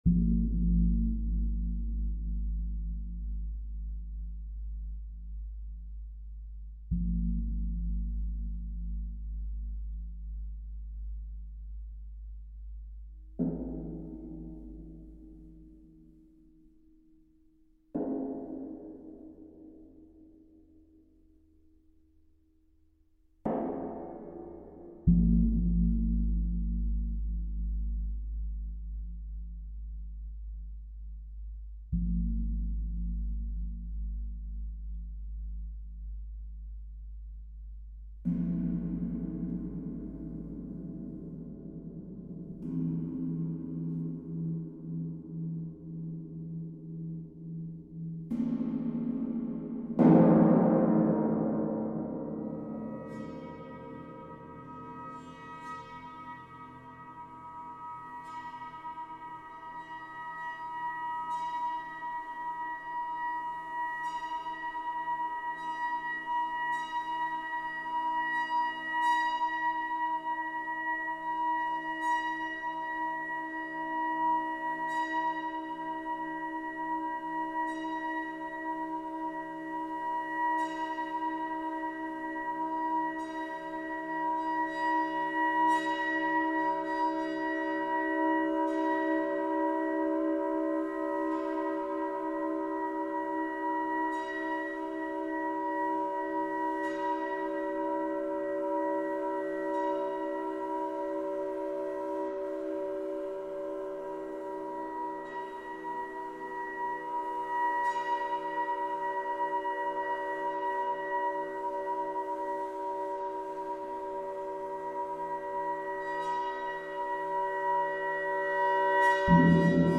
for solo Tam Tam